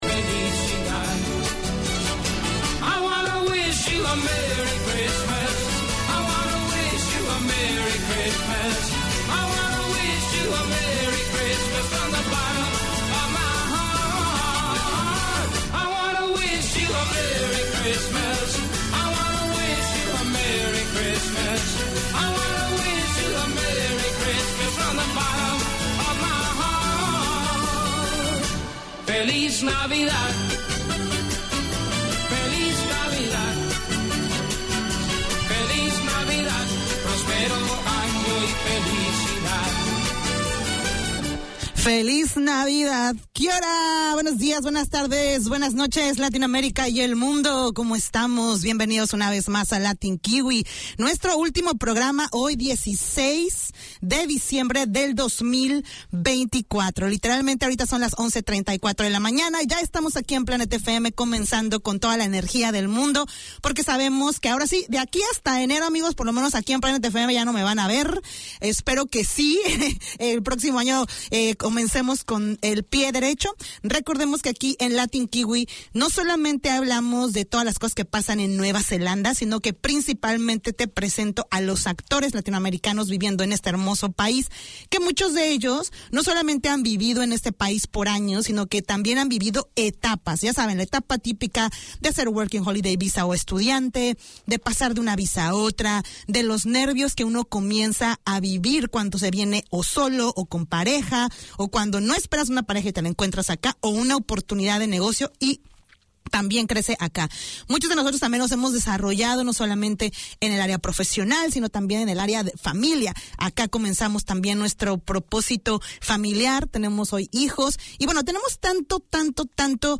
Latin Kiwi 4:25pm WEDNESDAY Community magazine Language: English Spanish Bienvenidos a todos!